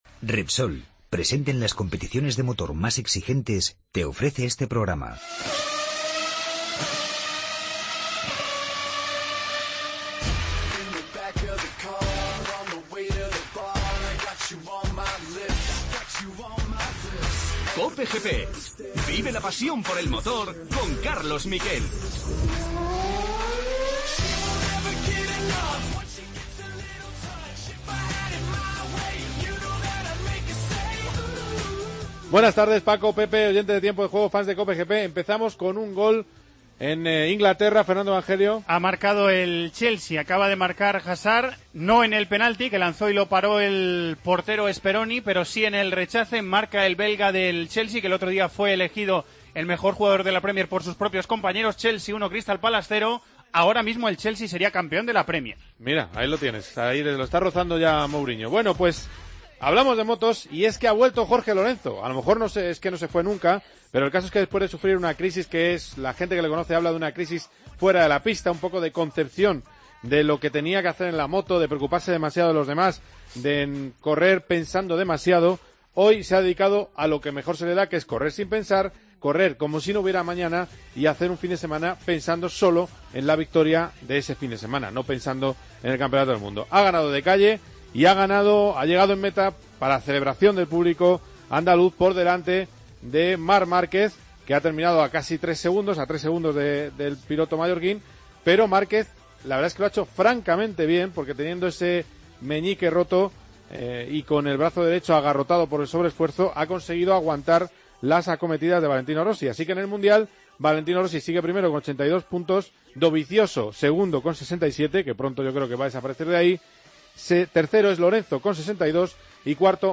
Analizamos el GP de España de motociclismo y escuchamos a Jorge Lorenzo y Marc Márquez. Entrevista a Tito Rabat, piloto de Moto2.